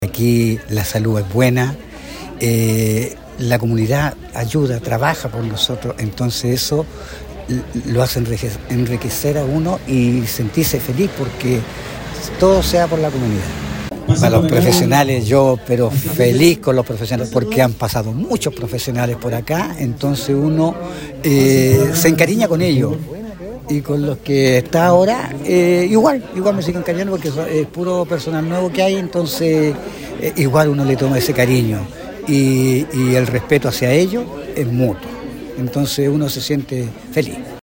Con una emotiva ceremonia que reunió a autoridades, profesionales de la salud y vecinos del sector poniente de Curicó, el Centro Comunitario de Salud Familiar (Cecosf) Prosperidad conmemoró un nuevo aniversario, reafirmando su rol como referente en la atención primaria.